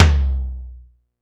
Tom Low.wav